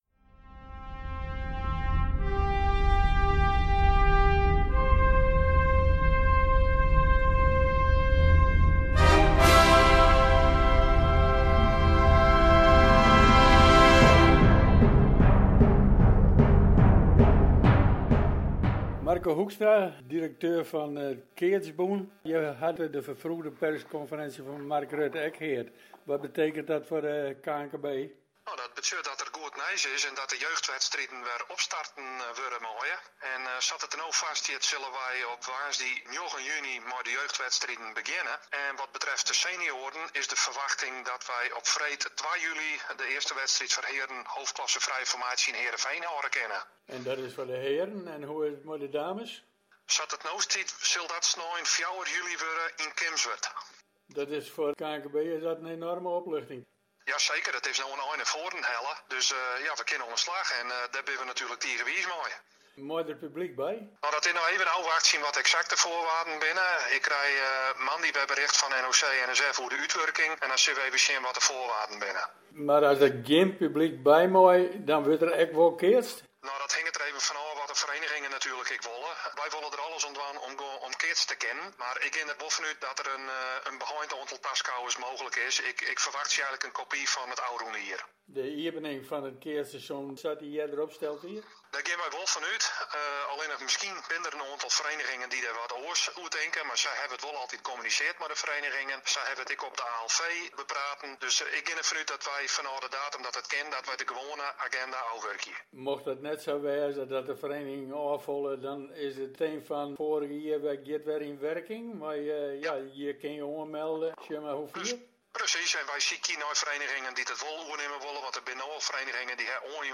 Underweis - Interviews - Sport - Onderweg